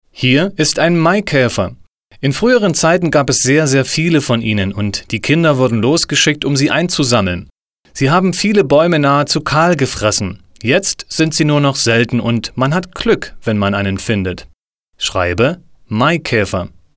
gesprochene Erklärung